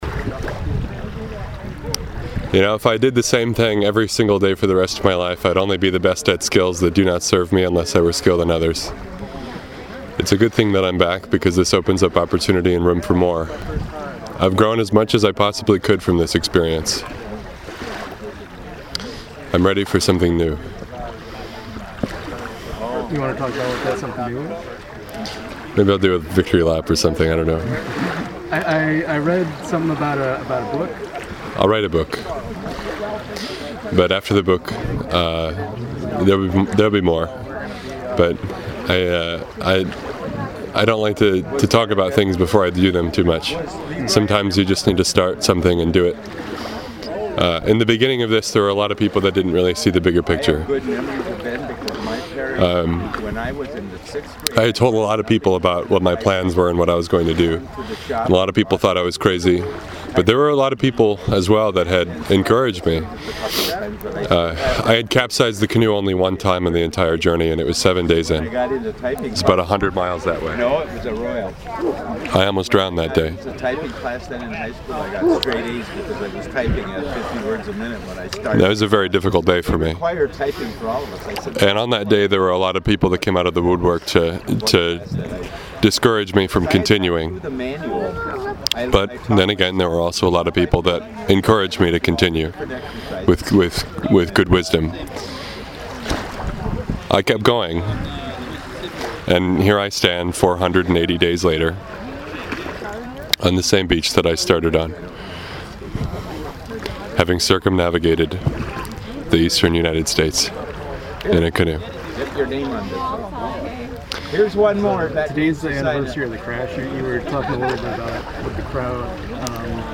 as the Lake Michigan waves splashed behind him.
choking back tears.